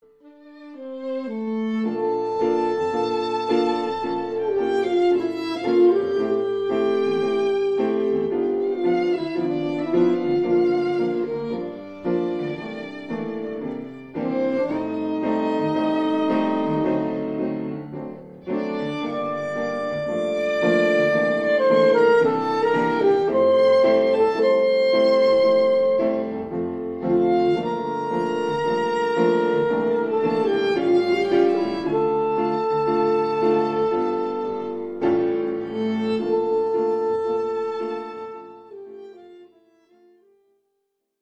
Deutsche Schlager der 20-40er Jahre
(Trio: Saxophon, Geige, Klavier)